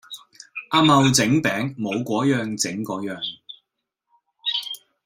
Голоса - Гонконгский 90